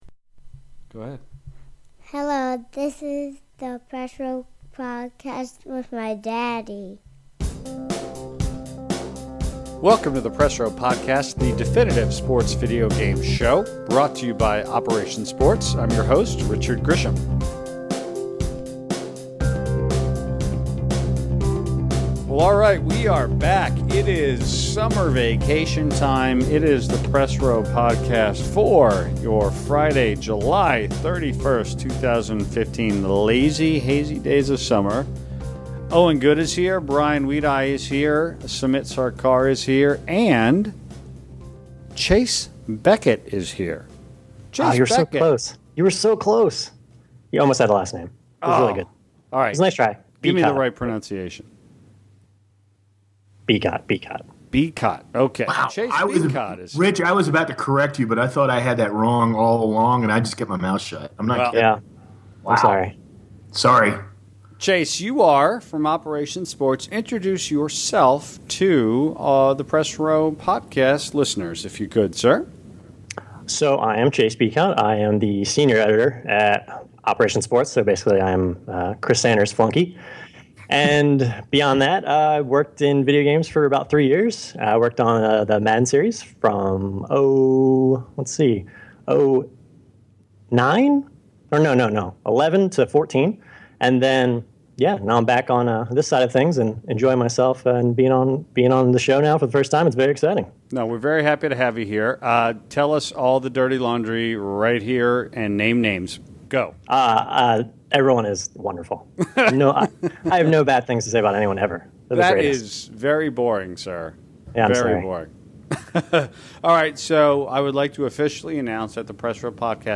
The 143rd episode of the Press Row Podcast gets our panel back together for a fun summer conversation. The crew chat about what they’ve been playing, the joys of Rocket League, the jammed fall sports schedule, the EASHL beta, and a whole lot more.